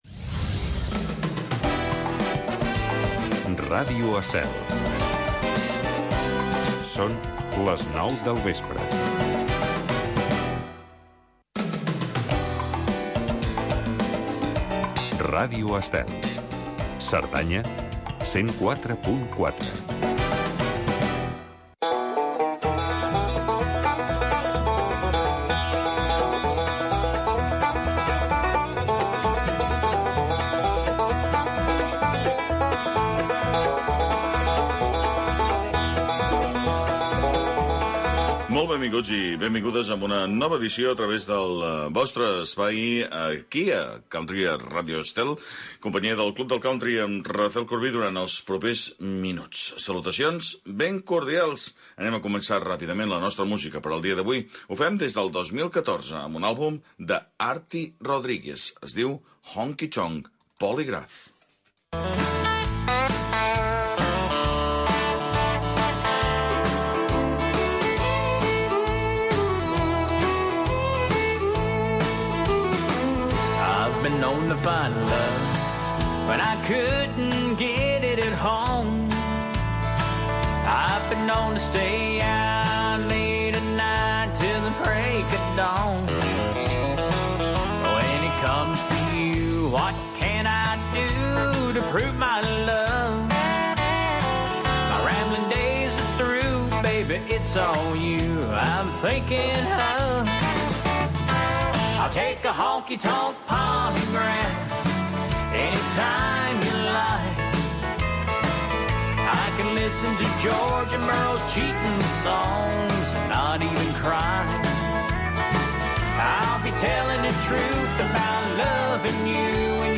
El club del country. Programa de música country. Durant 60 minuts escoltaràs els èxits del moment i els grans clàssics de la música country.